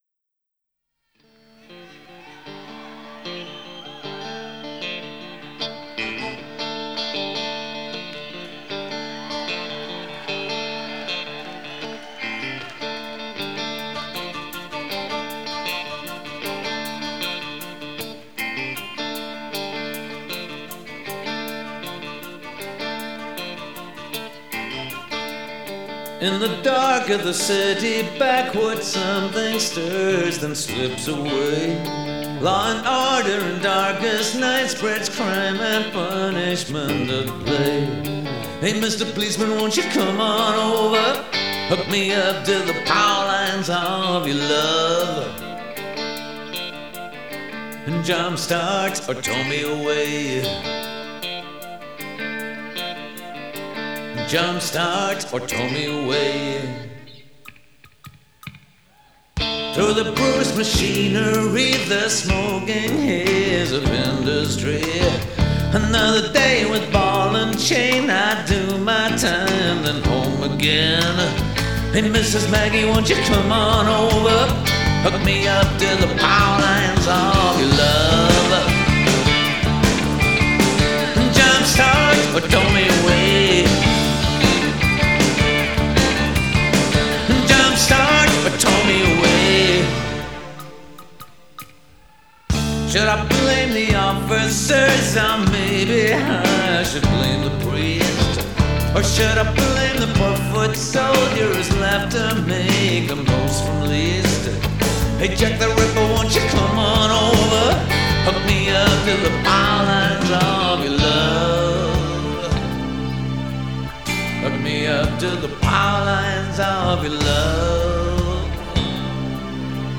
live 25.11.1987, the Tower Theater